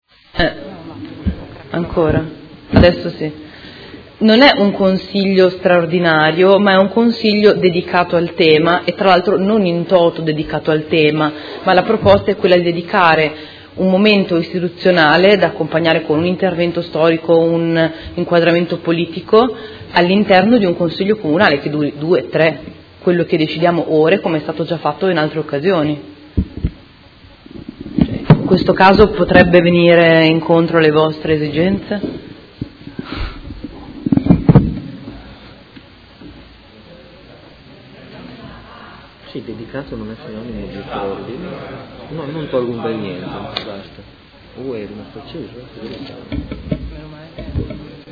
Federica Di Padova — Sito Audio Consiglio Comunale